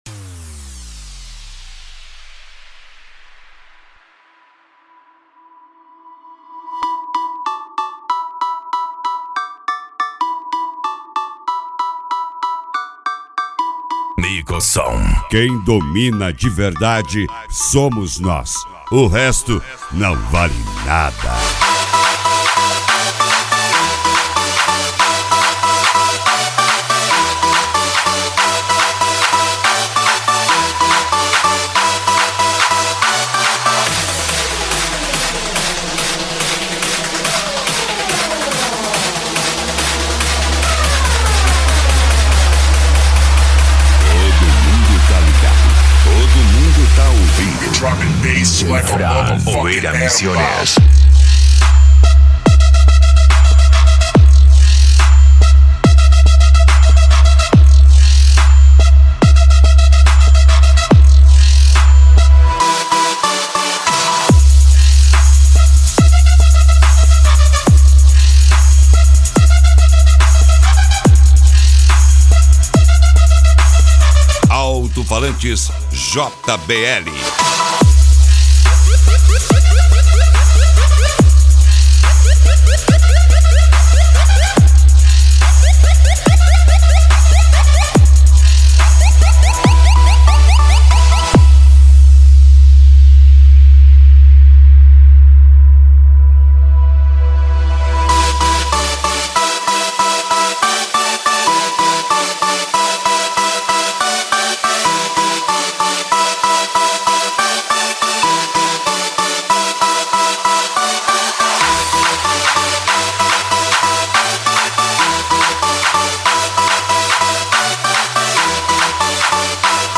Bass
Electro House
Eletronica
Psy Trance
Remix